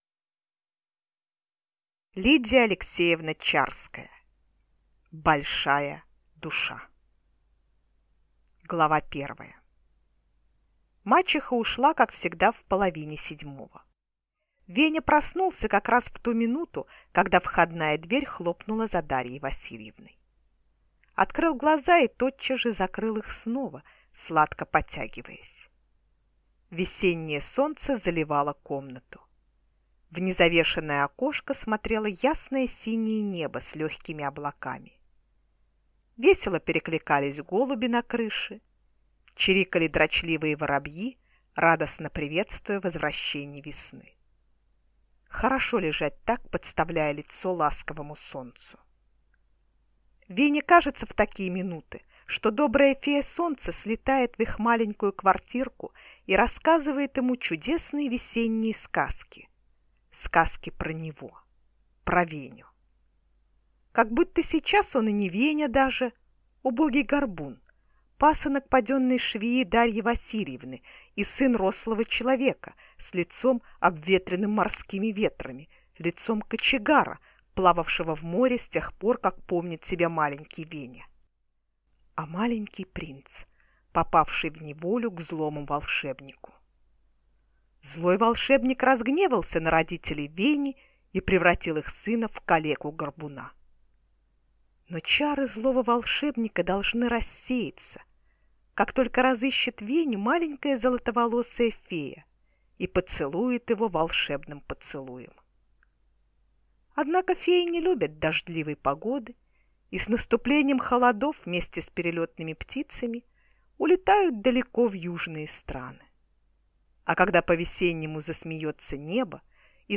Аудиокнига Большая душа | Библиотека аудиокниг